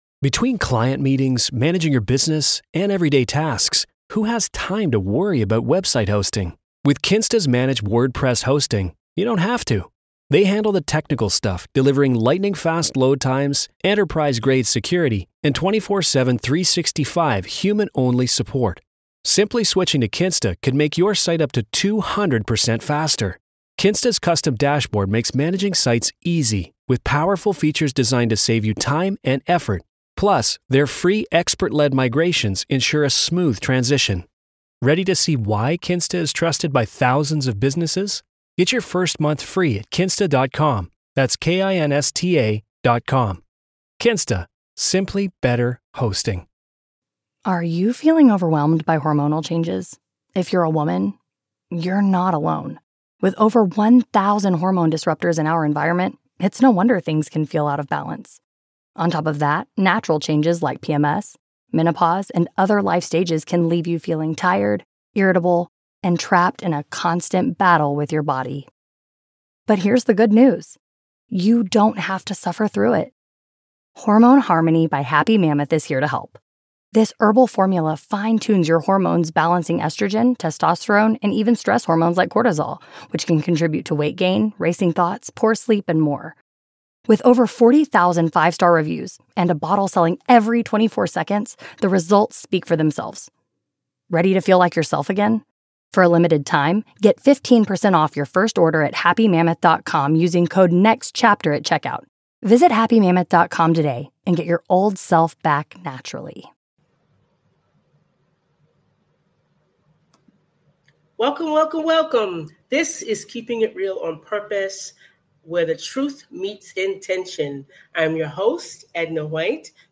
Join us for a mind-bending conversation